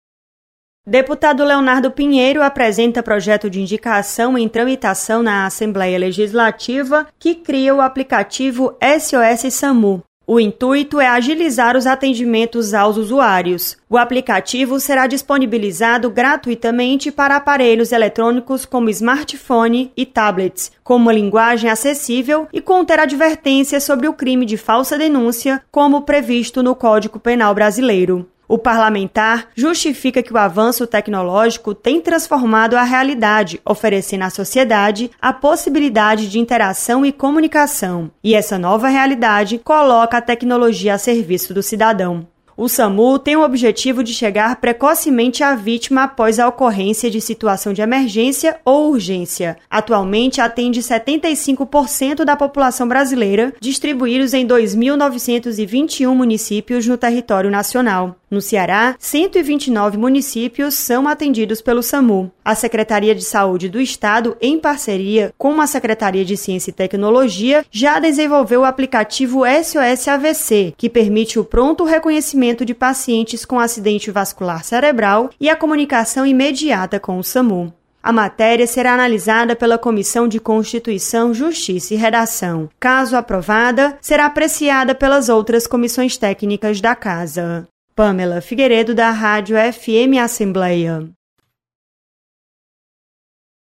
Deputado apresenta projeto para criar aplicativo que aciona o SAMU via Smartphones. Repórter